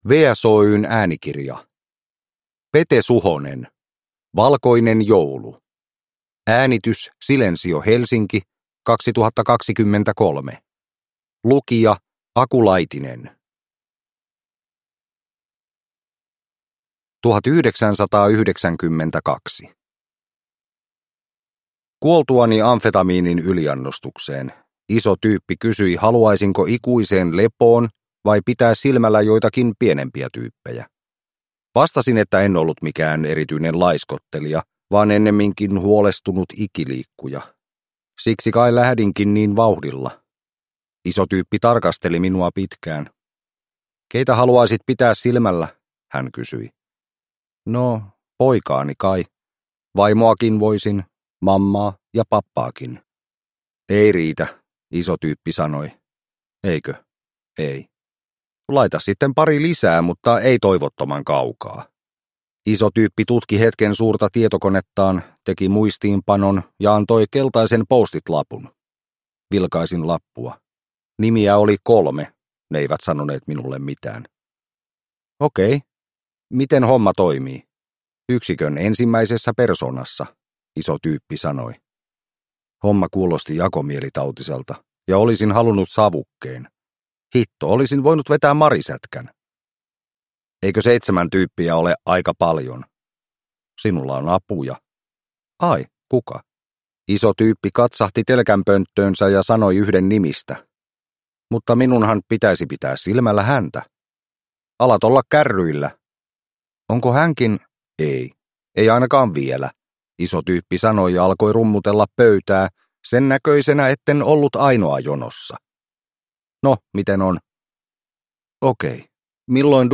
Valkoinen joulu – Ljudbok